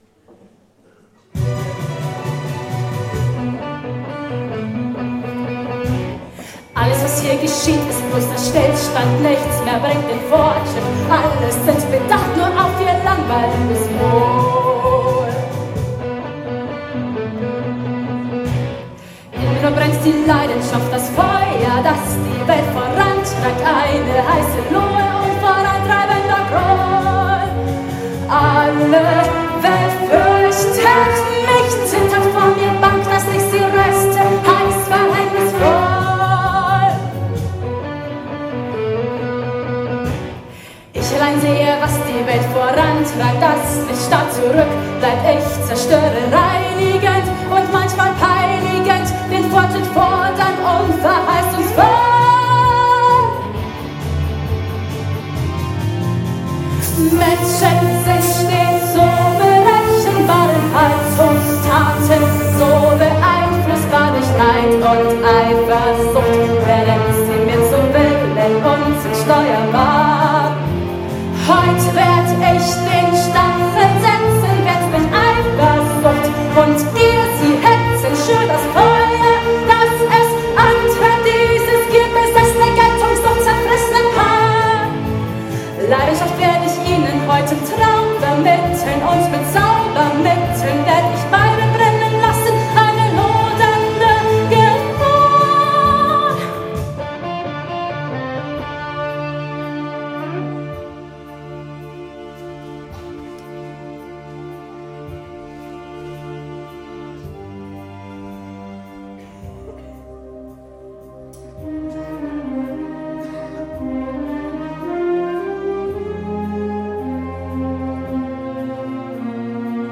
„Monolog des Feuers – Traumszene"  "Alles, was hier geschieht ist bloßer Stillstand!..."
15-monolog-d-f-traumsz.mp3